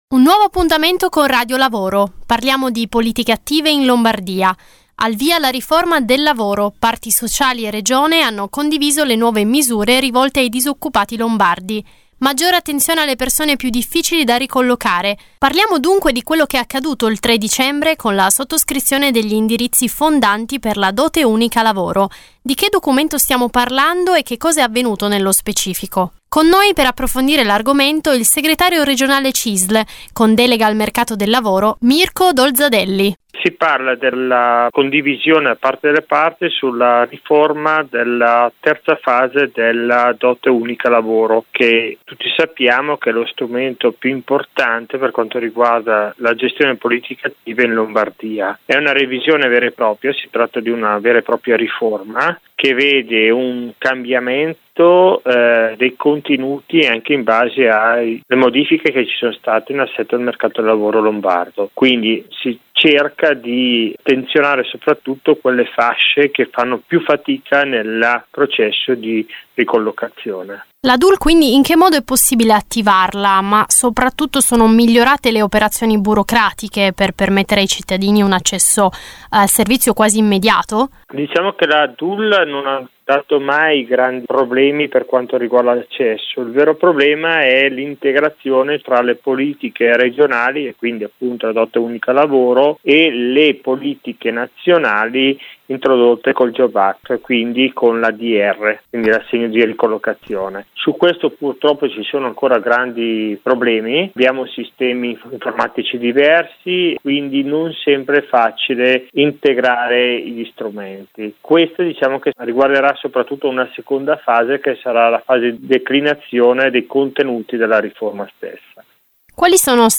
Di seguito la puntata del 6 dicembre di RadioLavoro, la rubrica d’informazione realizzata in collaborazione con l’ufficio stampa della Cisl Lombardia e in onda tutti il giovedì e il venerdì su Radio Marconi.
Questa settimana intervista